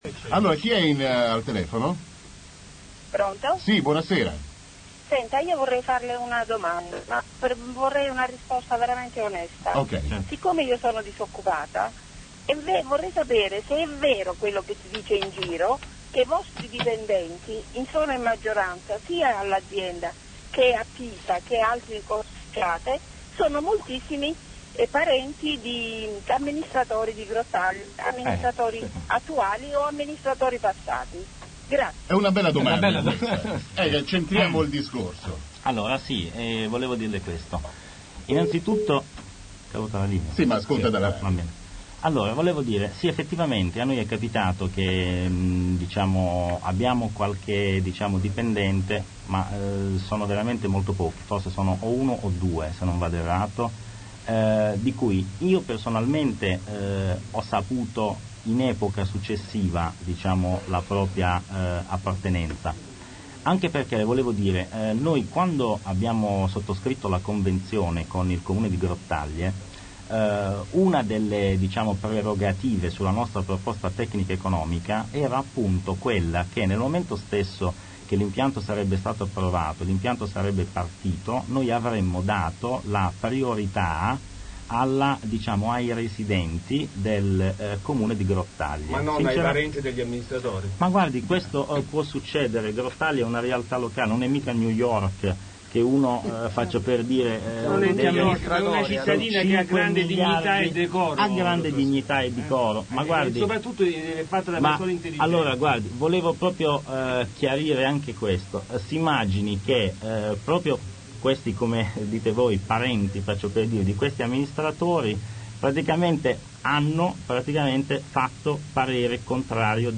Il comitato Vigiliamo per la discarica rende noto l’accluso stralcio, a stampa e in audio, della trasmissione radiofonica “Monitor” messa in onda il 26 luglio 2005 dalla emittente Radio Delta Uno Stereo di Grottaglie.
Potevano intervenire nella trasmissione anche i radioascoltatori.